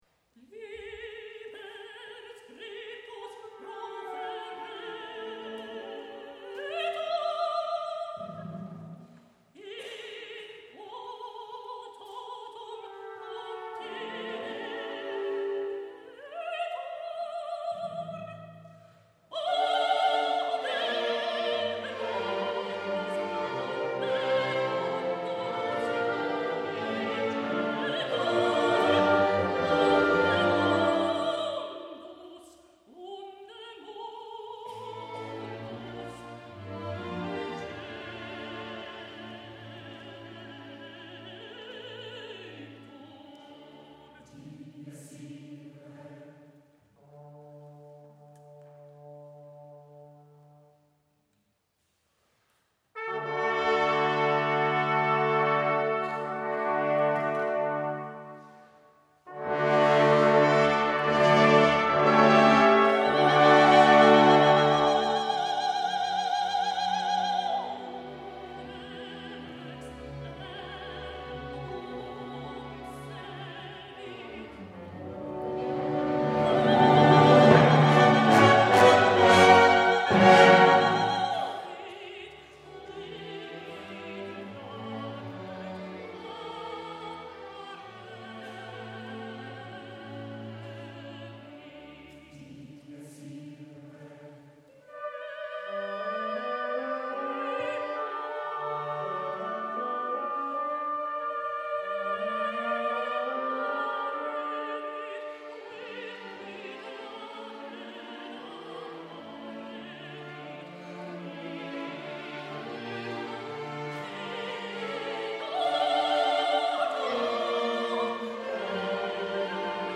Mezzosopran
Mitschnitt vom 10.11.2012, Nürtingen